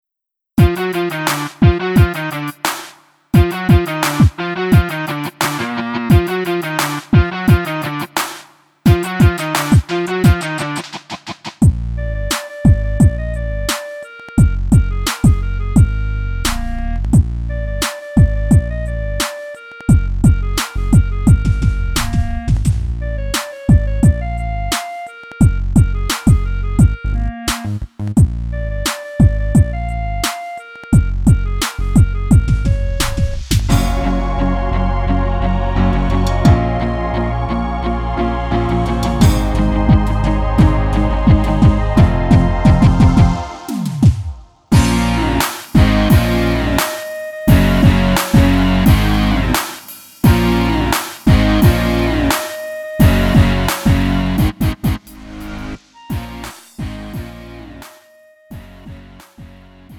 -멜로디MR MR 고음질 반주 다운로드.
음정 -1키
장르 가요